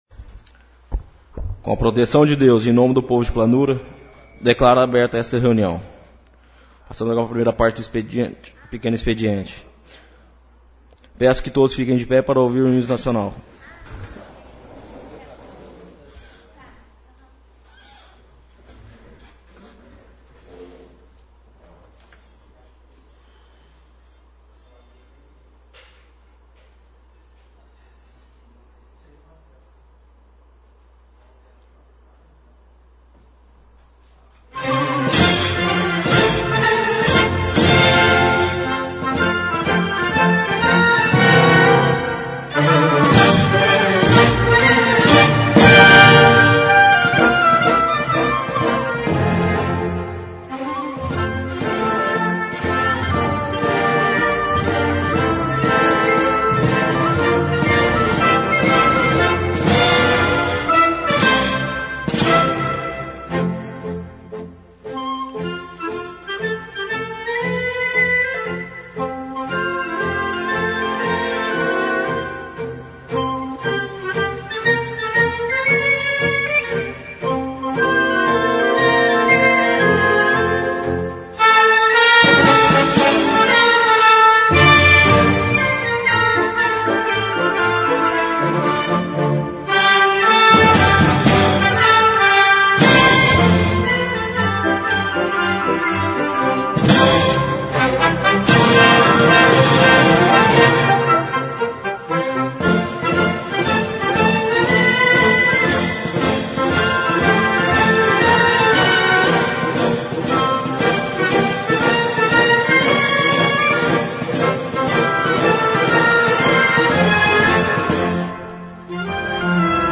Sessão Ordinária - 15/06/15